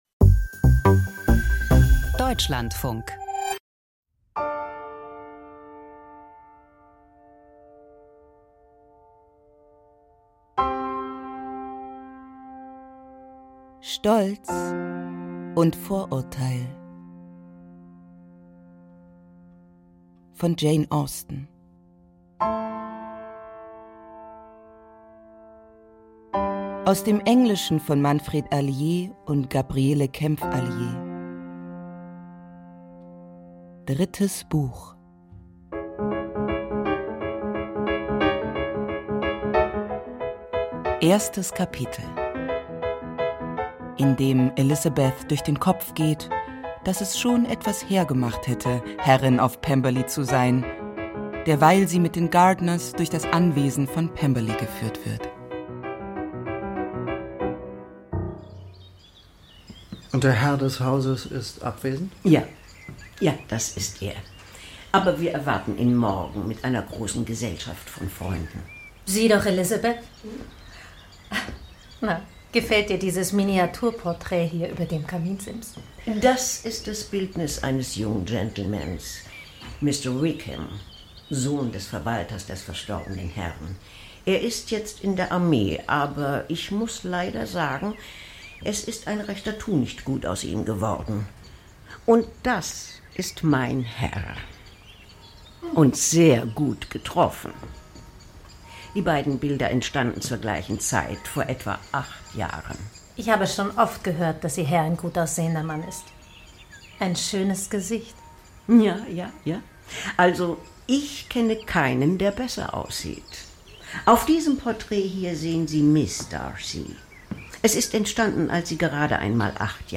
Aus dem Podcast Hörspiel Podcast abonnieren Podcast hören Podcast Hörspiel Klassiker der Literatur, spannende Dramen, unterhaltende Erzählungen und innovative Sound Art....